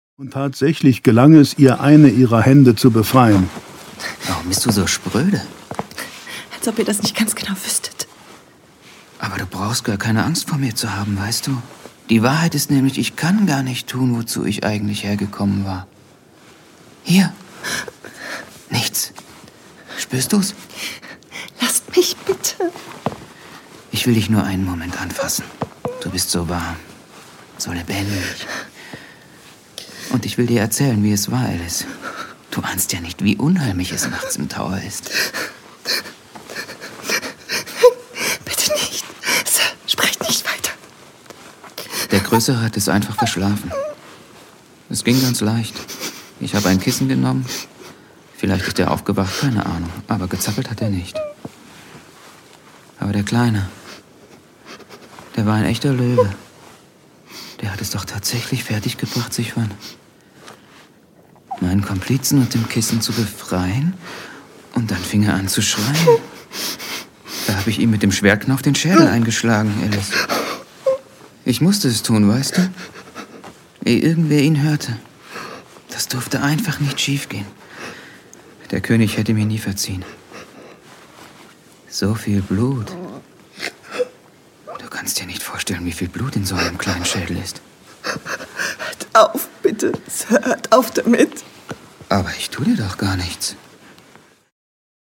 Rolle: Asmodina (John Sinclair Hörspiele)